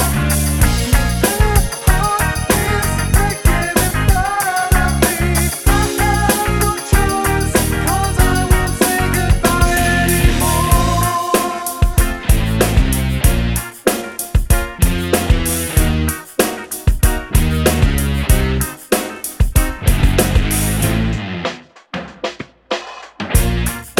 Two Semitones Down Pop (2000s) 3:32 Buy £1.50